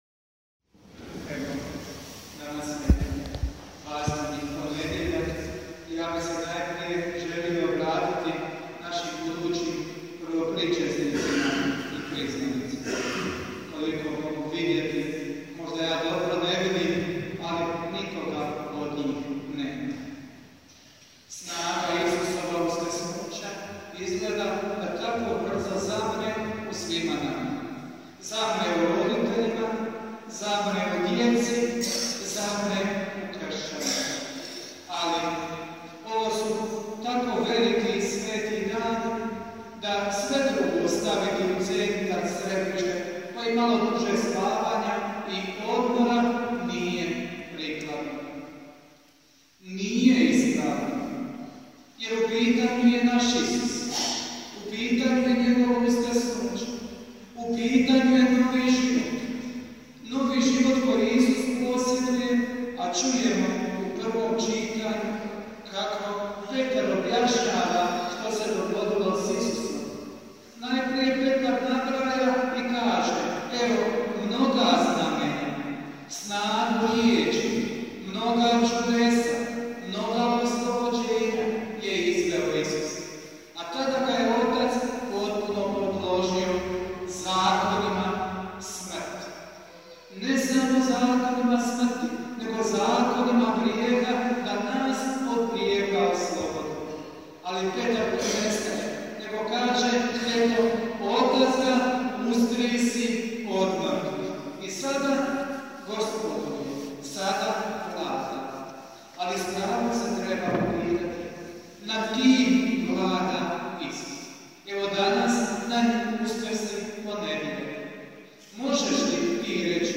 PROPOVIJED: